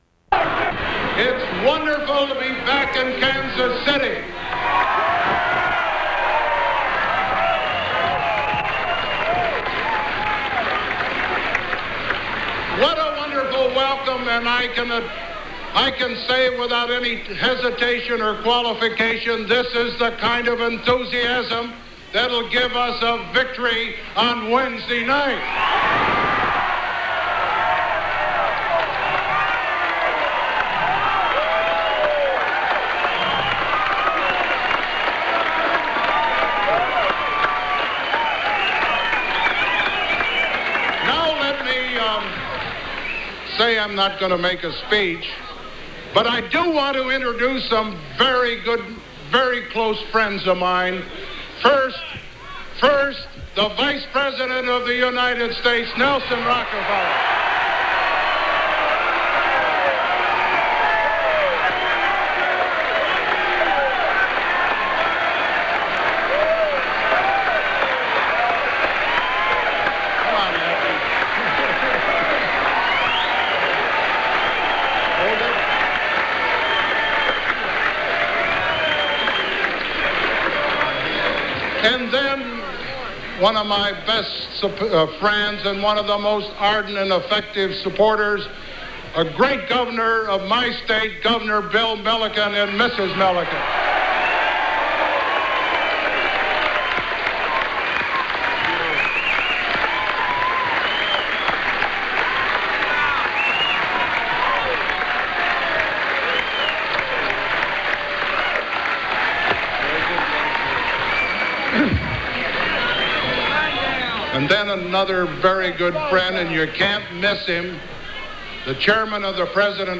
President Ford greets the excited crowds of Republicans on his arrival in Kansas City